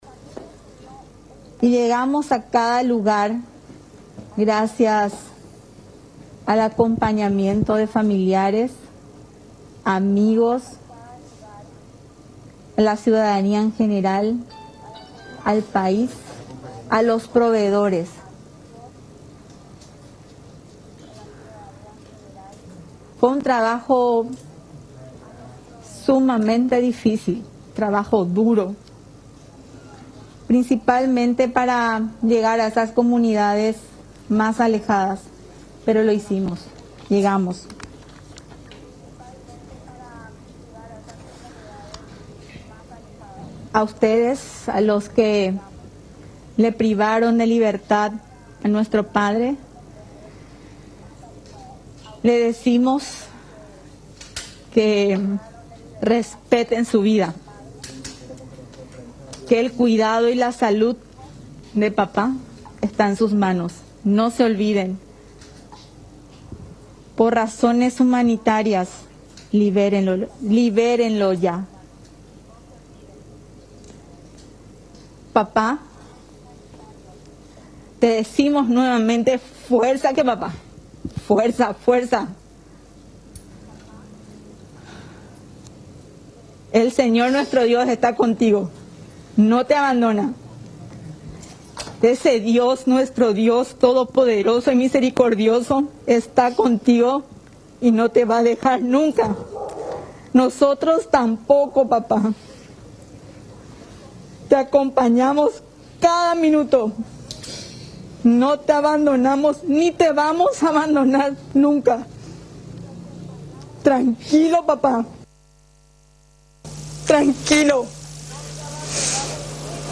CONFERENCIA-SECUESTRO.mp3